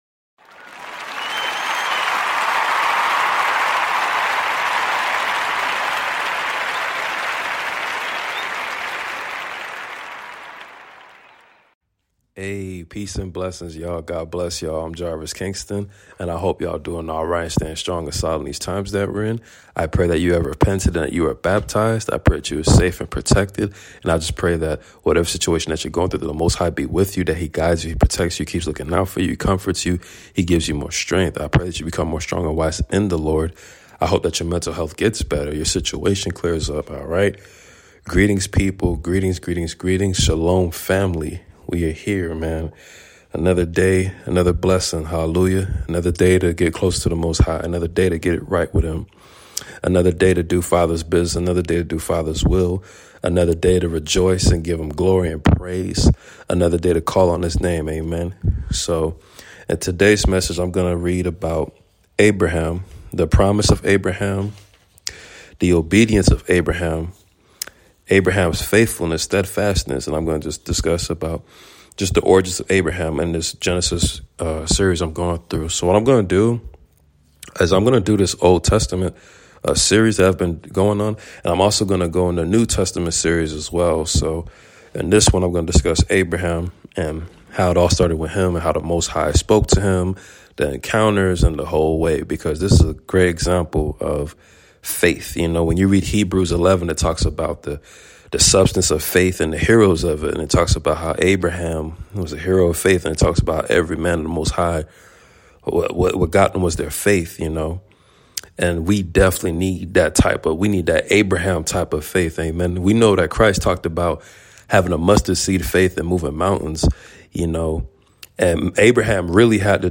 Genesis reading on Abraham’s faith and obedience!